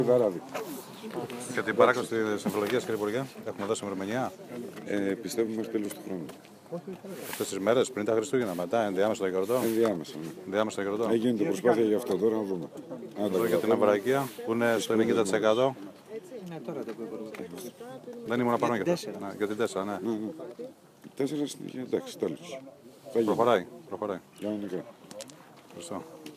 Δηλώσεις κ. Χρ. Σπίρτζη για την παράκαμψη Αμφιλοχίας από το εργοτάξιο της Αμβρακίας